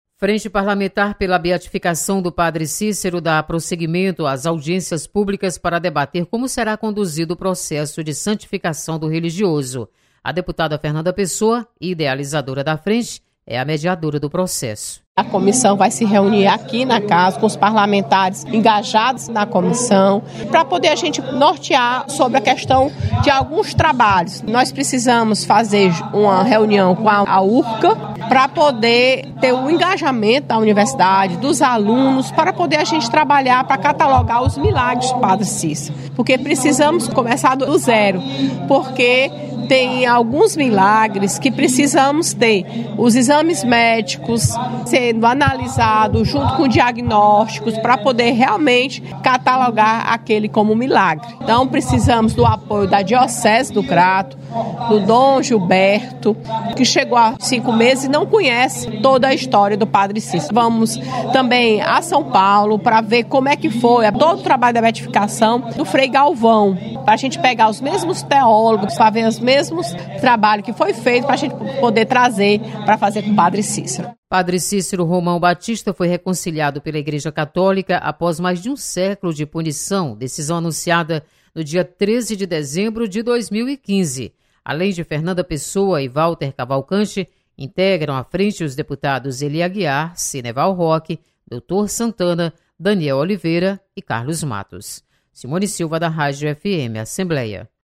Prosseguem as audiências públicas da Frente Parlamentar pela Beatificação do Padre Cícero. Repórter